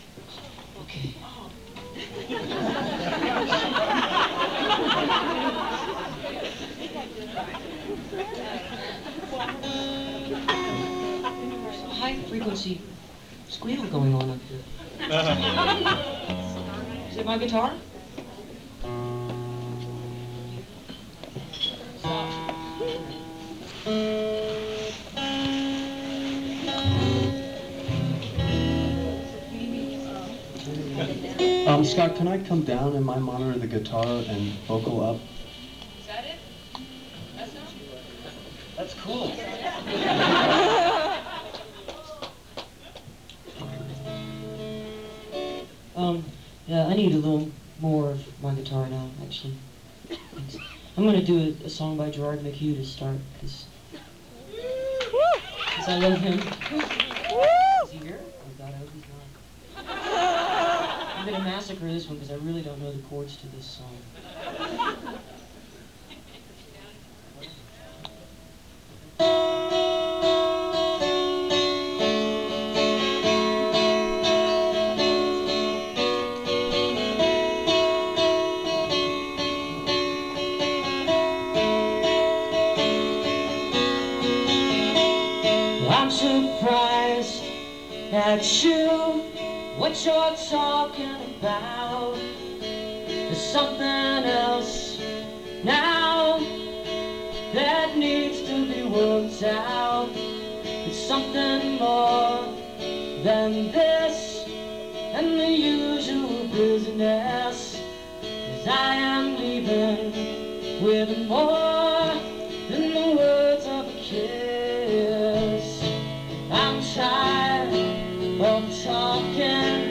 (acoustic show)